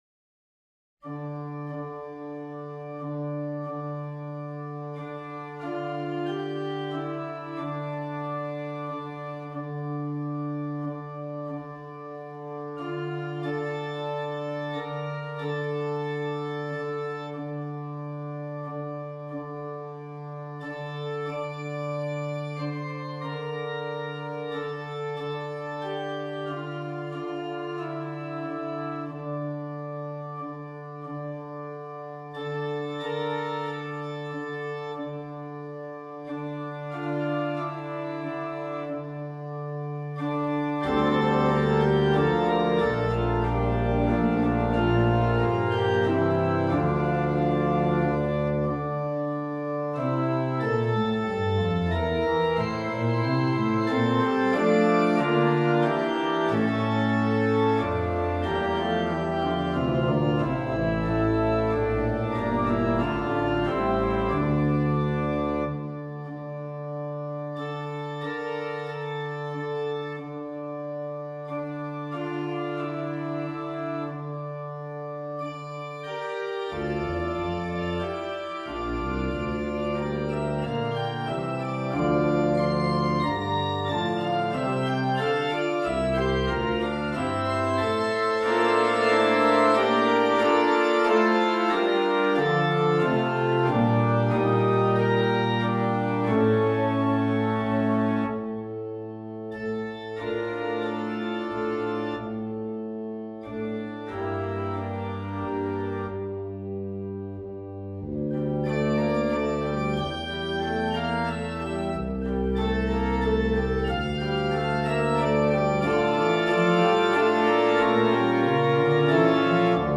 hymn tune